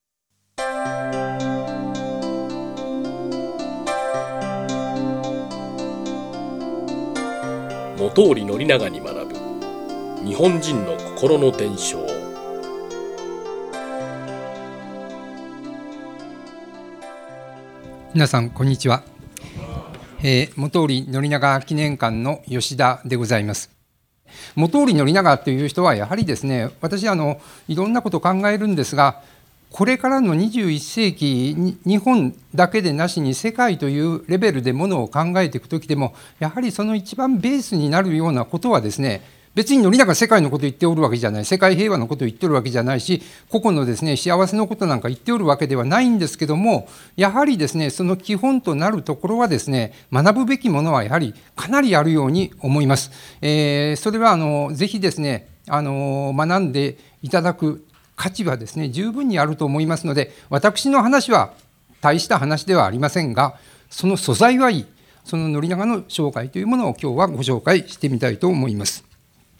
【収録内容】 ・学業と医業とを両立させた人生信条 ・宣長を支えた「考えて倦まず」の姿勢 ・松坂の一夜～賀茂真淵の志を受継ぐ ・恩をつなぎ、恩を送る～恩頼図に学ぶ 【収録時間】 78分21秒 ※この音声は平成28年9月17日に開催された致知出版社主催「一日セミナー『日本の偉人に学ぶ』」での講演を収録したものです。